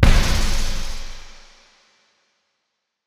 Big Drum Hit 32.wav